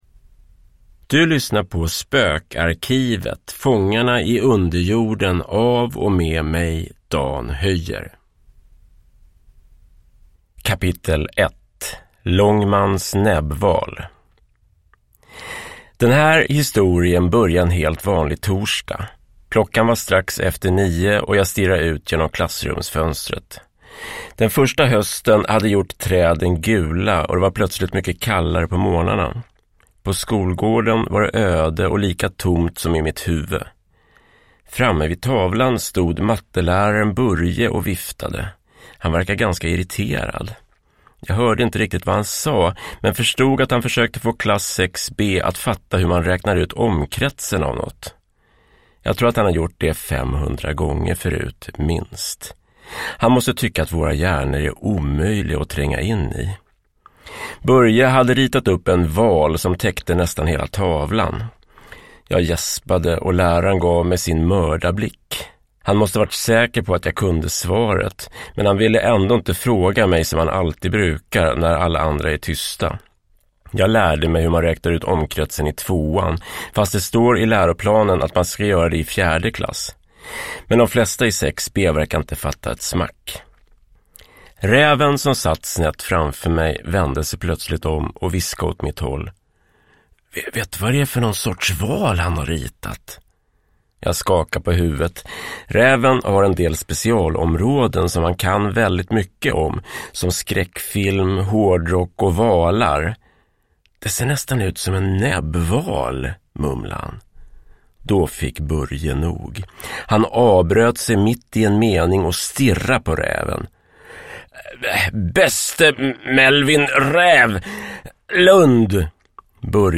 Fångarna i underjorden – Ljudbok